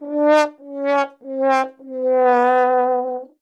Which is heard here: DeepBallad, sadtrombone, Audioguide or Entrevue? sadtrombone